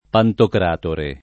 pantocratore [